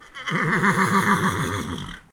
horse-whinny-1.ogg